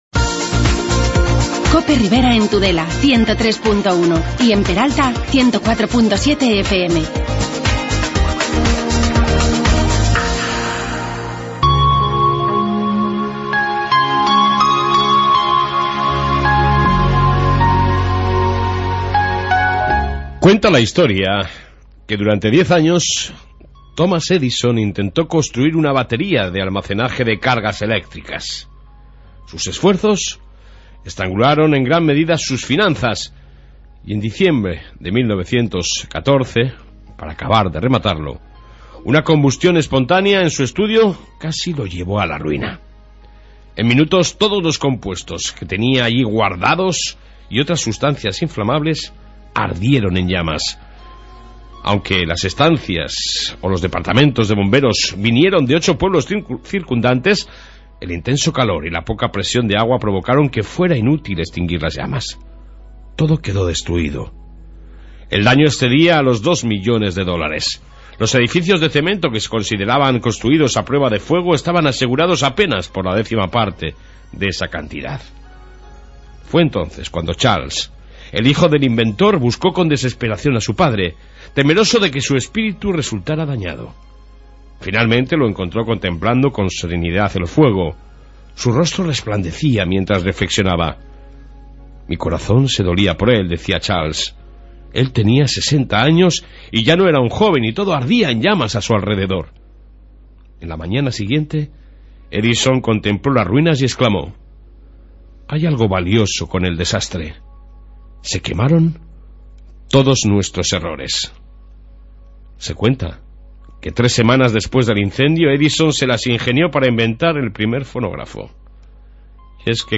AUDIO: Hoy en Cope Ribera invitamos a Ecologistas en Acción de la Ribera - Navarraa charlar de 2 temas de interés...La circulación en...